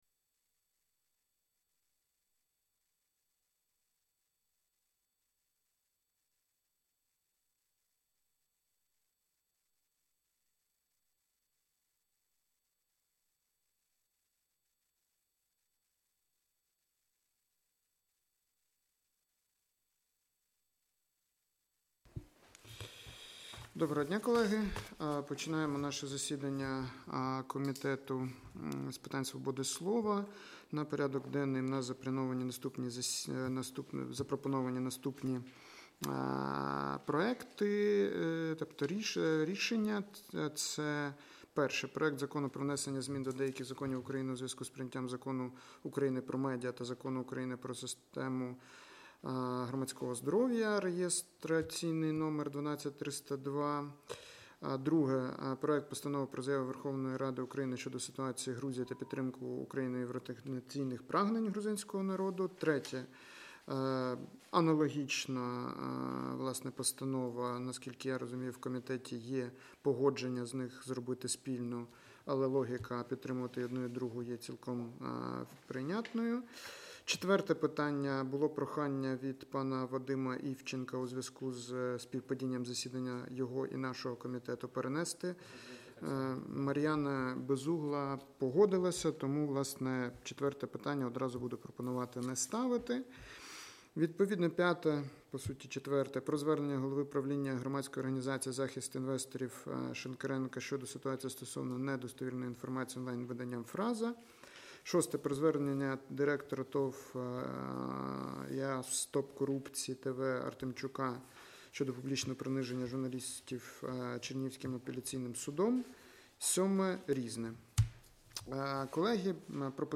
Аудіозапис засідання Комітету від 19 грудня 2024р.